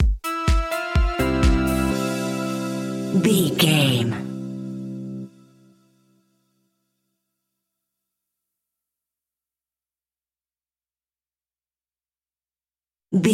Joyful Tropical House Stinger.
Ionian/Major
groovy
uplifting
driving
energetic
repetitive
drums
synthesiser
drum machine
electric piano
house
electro house
synth bass